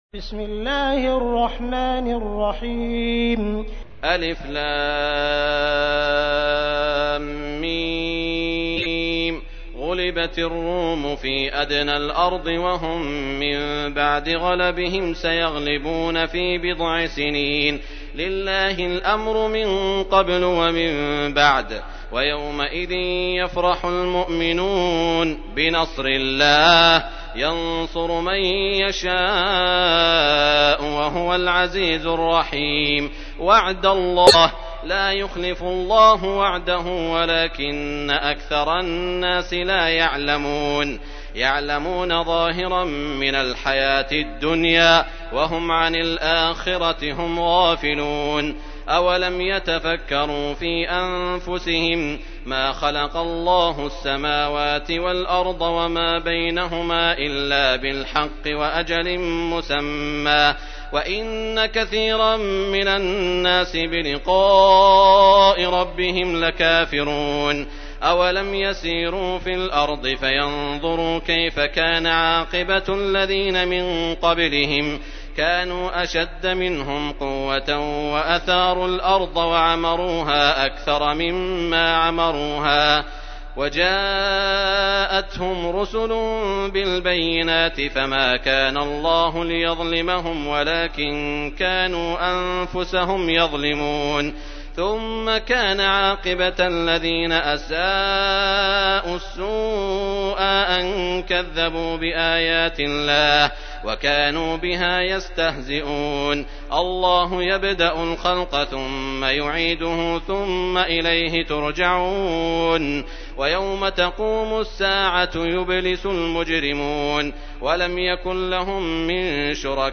تحميل : 30. سورة الروم / القارئ عبد الرحمن السديس / القرآن الكريم / موقع يا حسين